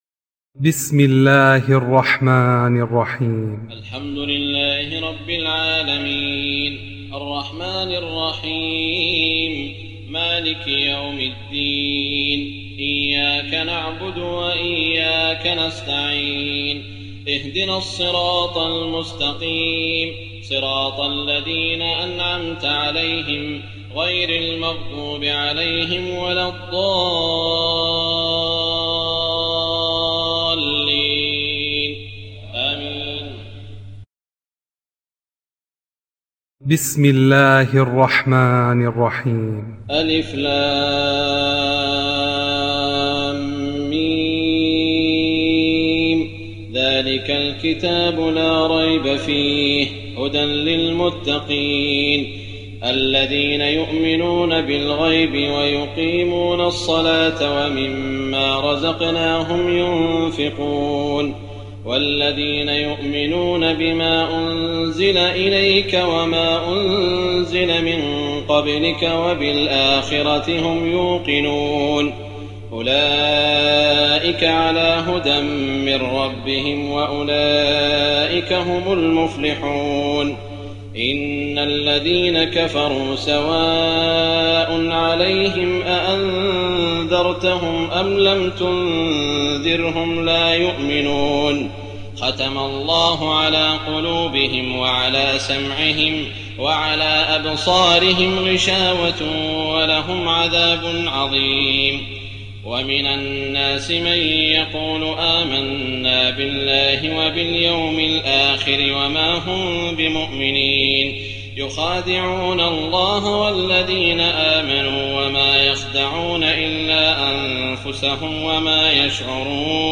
تراويح الليلة الأولى رمضان 1419هـ من سورة البقرة (1-74) Taraweeh 1st night Ramadan 1419H from Surah Al-Baqara > تراويح الحرم المكي عام 1419 🕋 > التراويح - تلاوات الحرمين